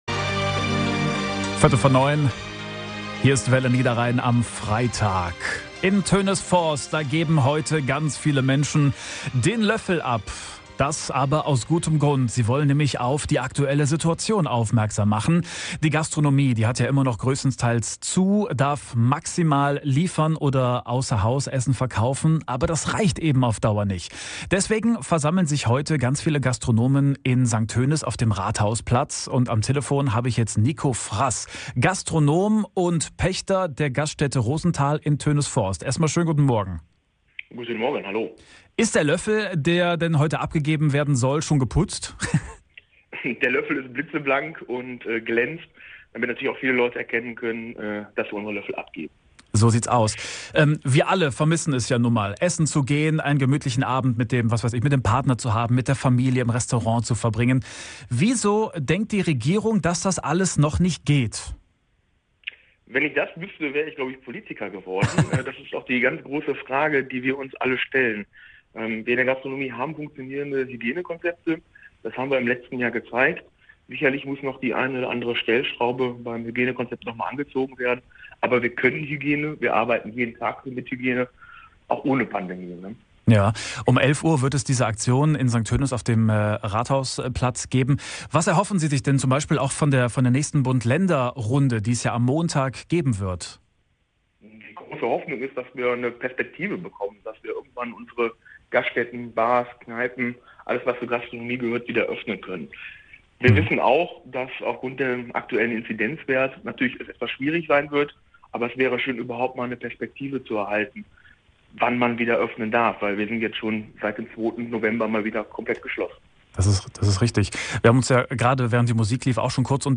Im Live-Interview